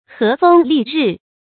發音讀音
成語簡拼 hflr 成語注音 ㄏㄜˊ ㄈㄥ ㄌㄧˋ ㄖㄧˋ 成語拼音 hé fēng lì rì 發音讀音 常用程度 一般成語 感情色彩 中性成語 成語用法 作賓語、定語；用于天氣描寫 成語結構 聯合式成語 產生年代 古代成語 近義詞 風和日麗 成語例子 那時節和風麗日滿東園。